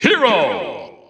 The announcer saying Hero's name in English releases of Super Smash Bros. Ultimate.
Hero_English_Announcer_SSBU.wav